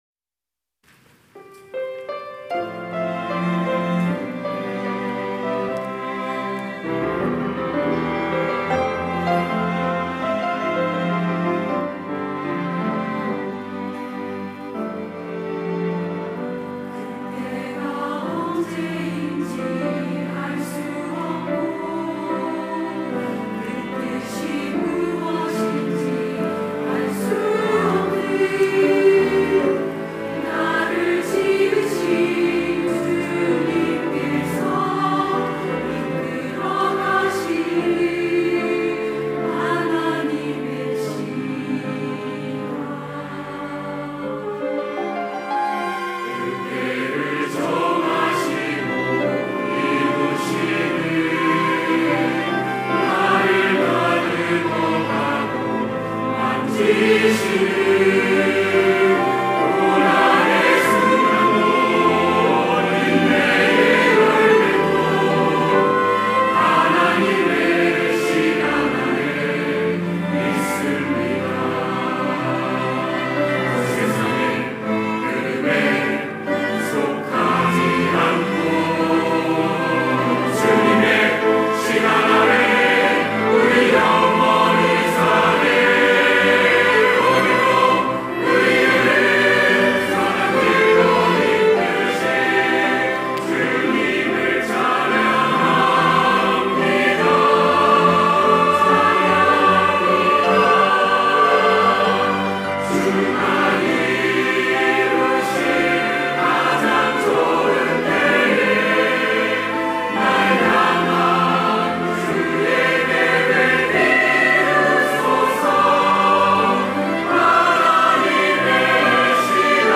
호산나(주일3부) - 하나님의 시간
찬양대 호산나